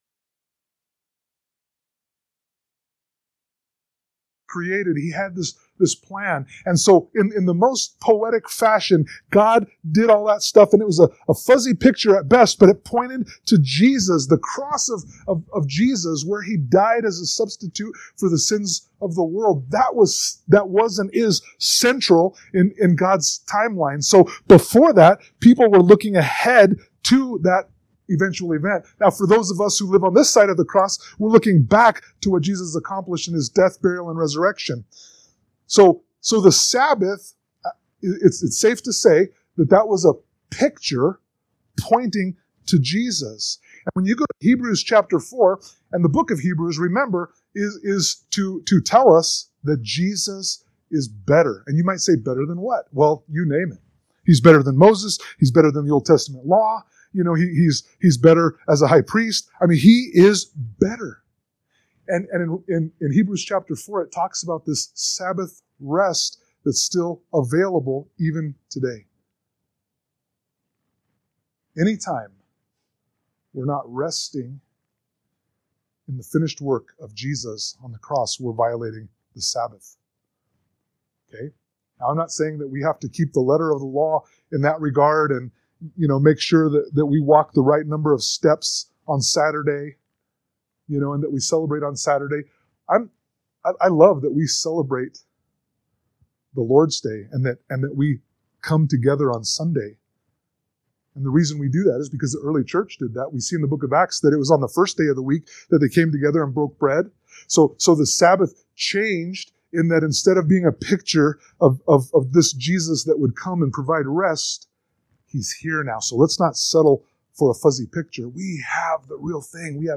Sermon-7_6_25.mp3